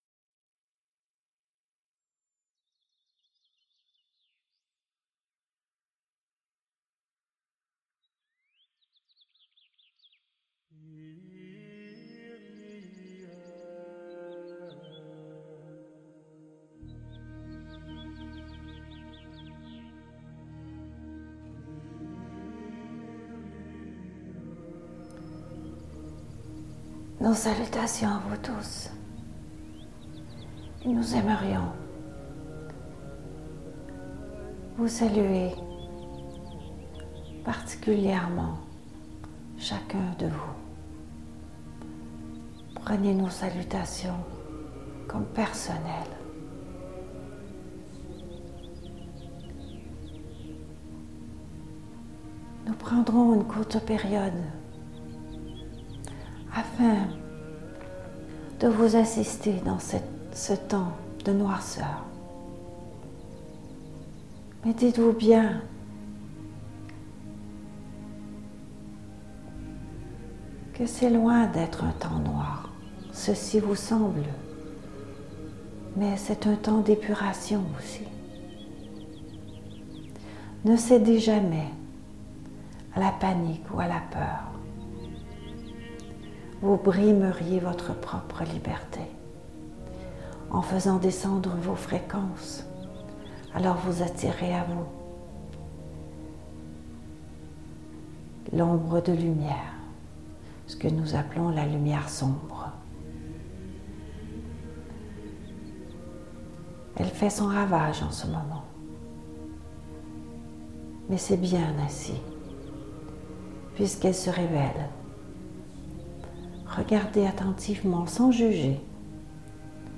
Si vous vous posez la question, il s’agissait de l’album Illumination de Dan Gibson.